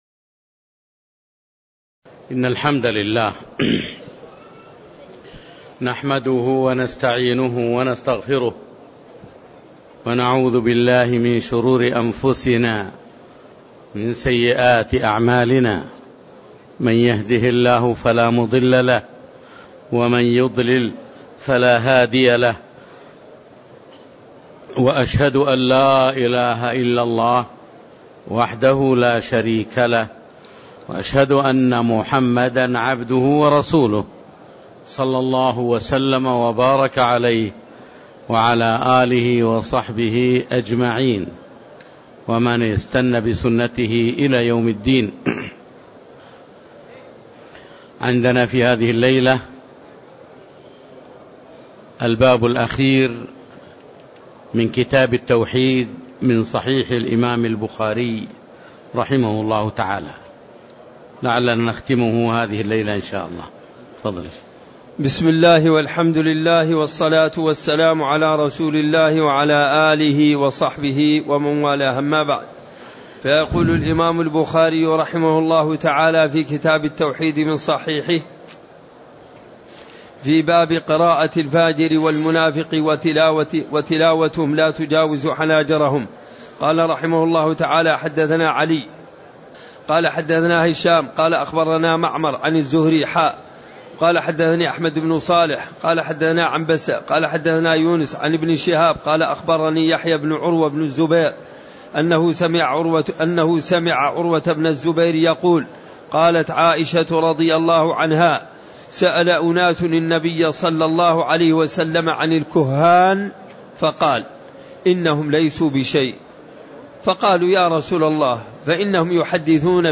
تاريخ النشر ١٤ رجب ١٤٣٦ هـ المكان: المسجد النبوي الشيخ